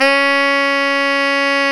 Index of /90_sSampleCDs/Roland LCDP07 Super Sax/SAX_Tenor mf&ff/SAX_Tenor ff
SAX TENORF0F.wav